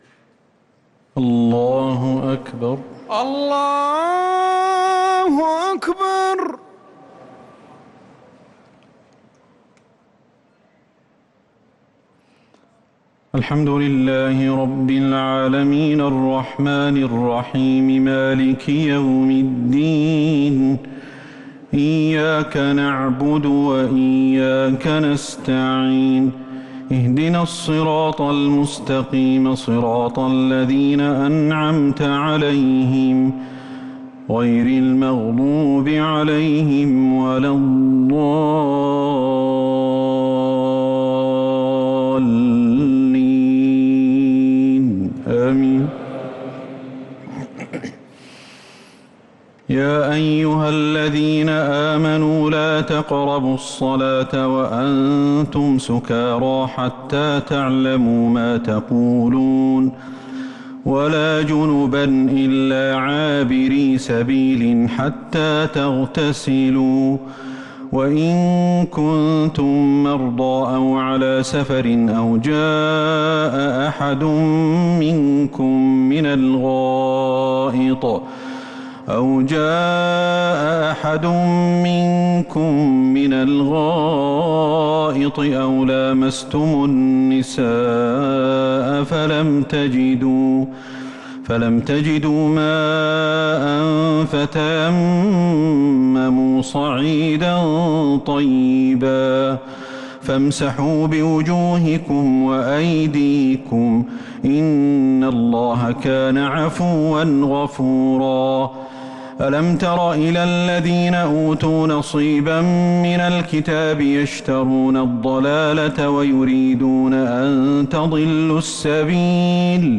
صلاة التراويح ليلة 6 رمضان 1443 للقارئ أحمد الحذيفي - التسليمتان الأخيرتان صلاة التراويح